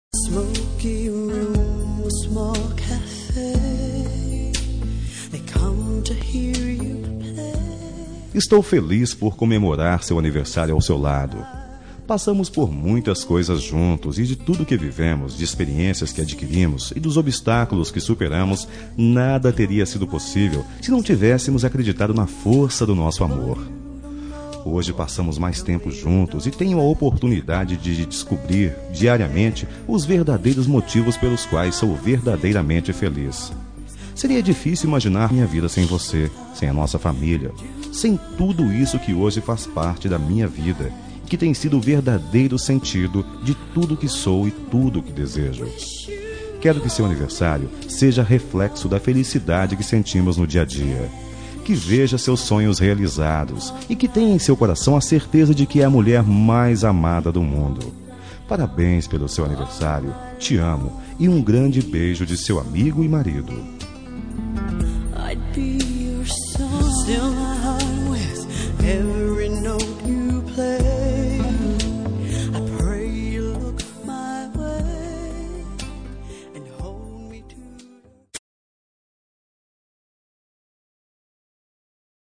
Telemensagem de Aniversário de Esposa – Voz Masculina – Cód: 1116 Linda